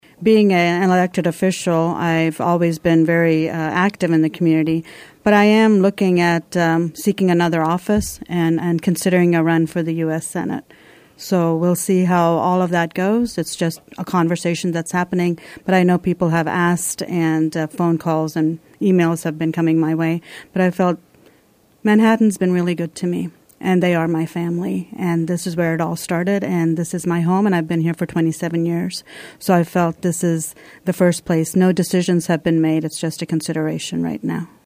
Earlier this week, speaking on KMAN’s In Focus, Mayor Pro Tem Usha Reddi confirmed she is considering a possible Senate run for the seat occupied currently by Pat Roberts.